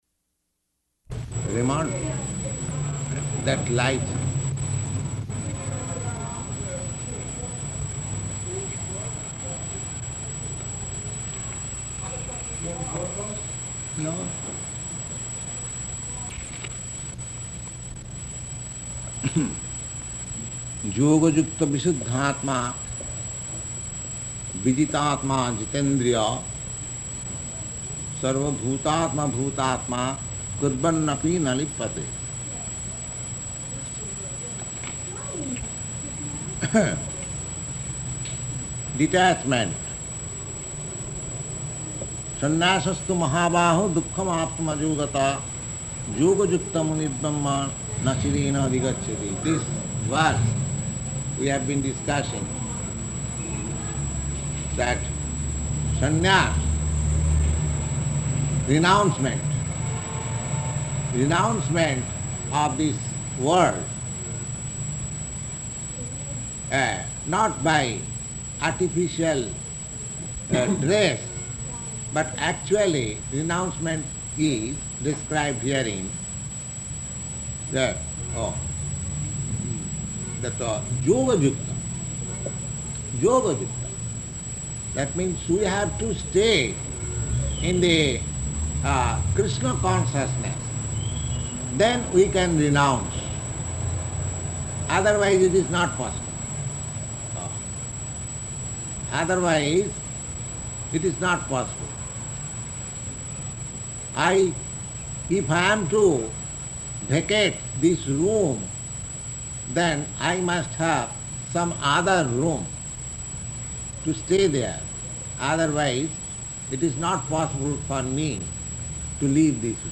-- Type: Bhagavad-gita Dated: August 26th 1966 Location: New York Audio file